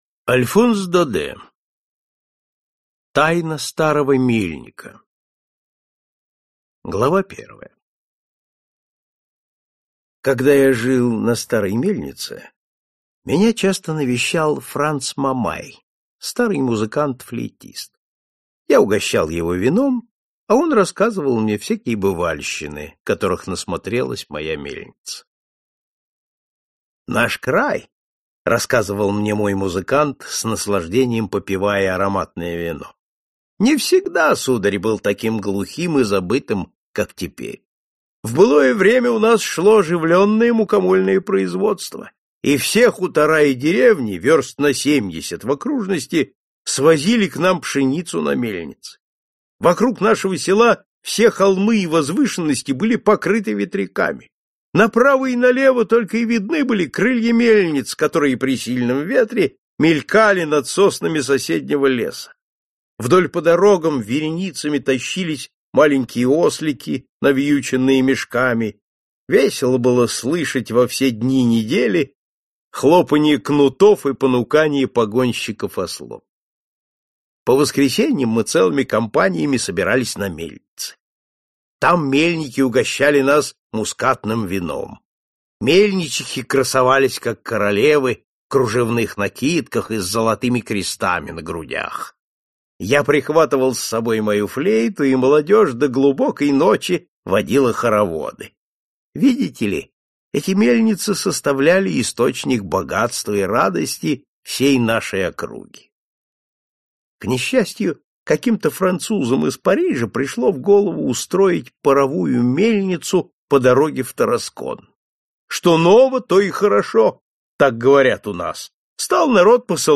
Аудиокнига Классика зарубежного рассказа № 15 | Библиотека аудиокниг